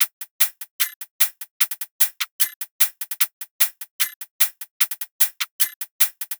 VR_top_loop_izze_150.wav